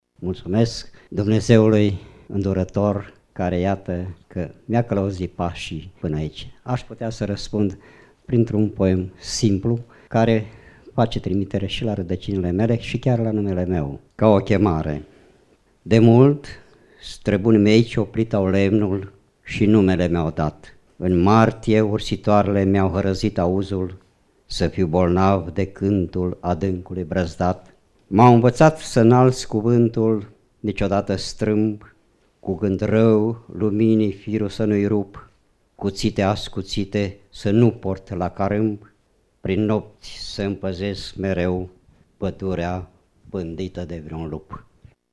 Ascultăm un fragment dintr-un poem rostit acum 4 ani, cu ocazia zilei sale de naștere:
stiri-4-ian-Ladariu-poem.mp3